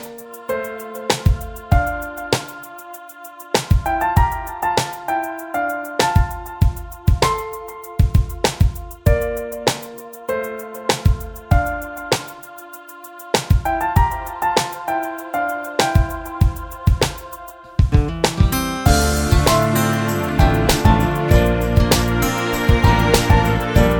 Minus All Guitars Pop